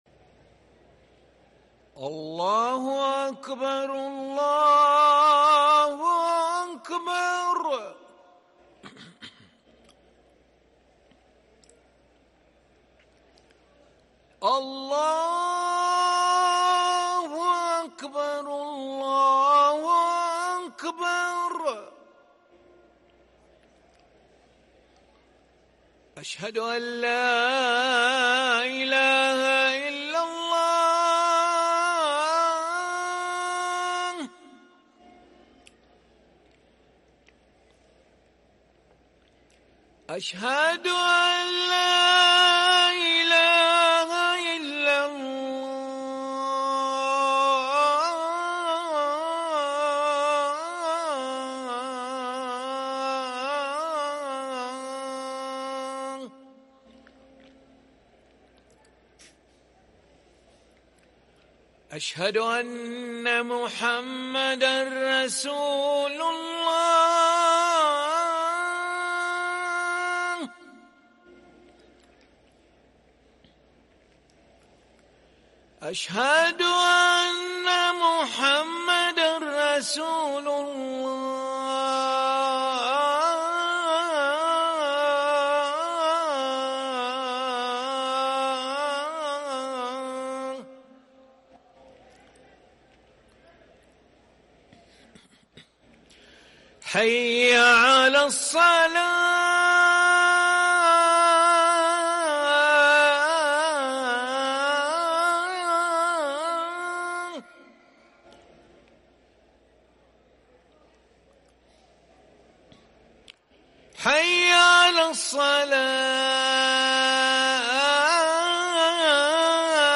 أذان العشاء للمؤذن علي ملا الخميس 12 صفر 1444هـ > ١٤٤٤ 🕋 > ركن الأذان 🕋 > المزيد - تلاوات الحرمين